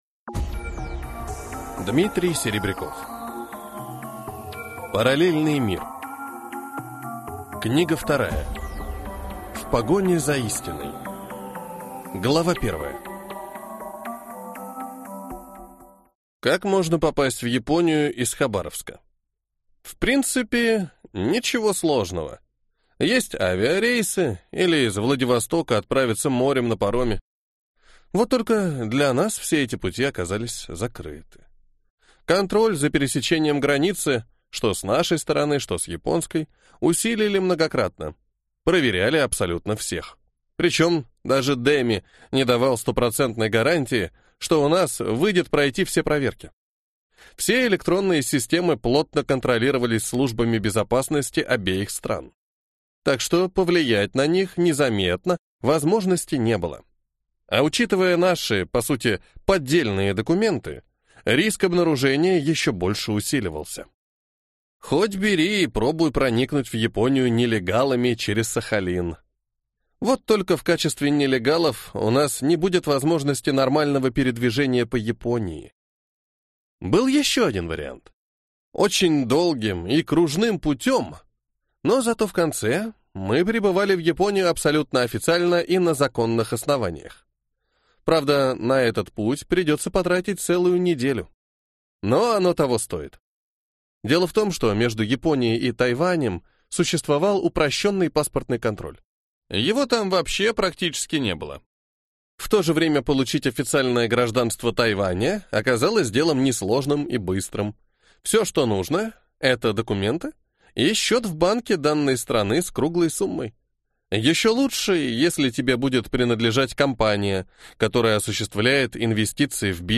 Аудиокнига Параллельный мир. Книга 2. В погоне за истиной | Библиотека аудиокниг